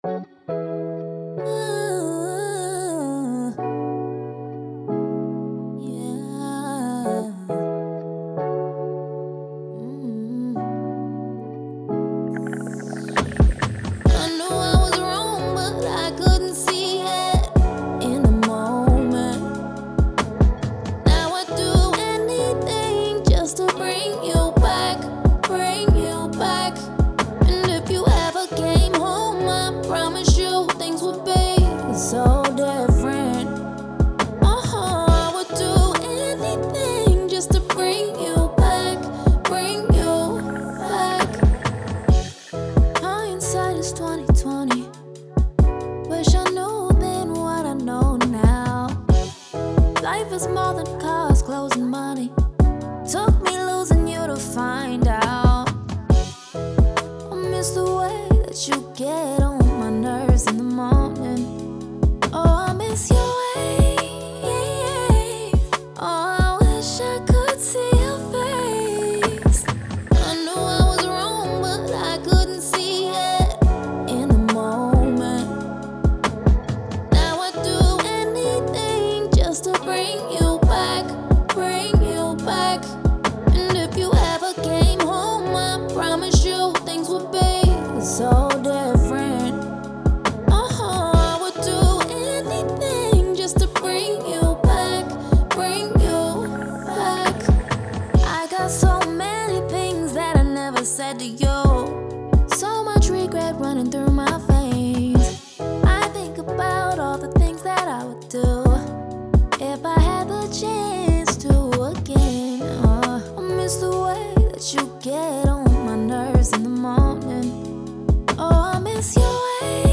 R&B
Bb Minor